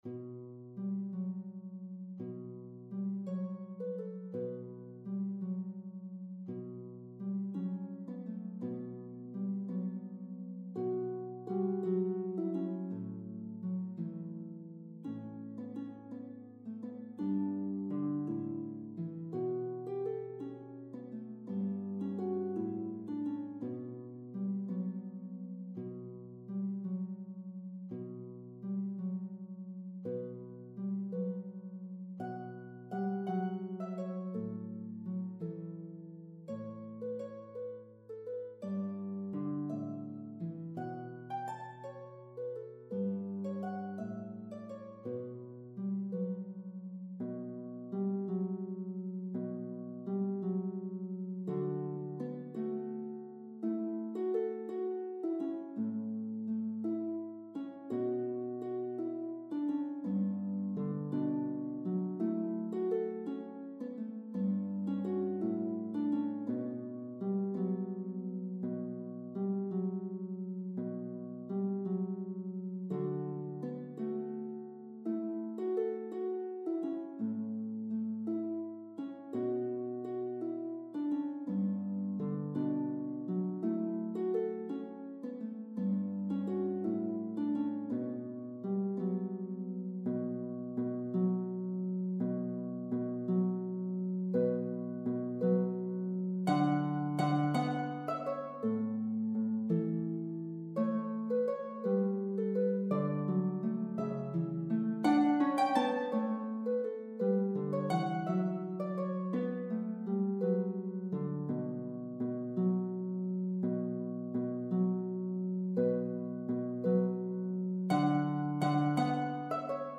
Scottish Gaelic song